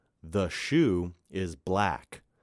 描述：句子："我穿着雨衣"用男声。用舒尔SM57话筒录制。
标签： 听觉 男性 穿 雨衣 培训
声道立体声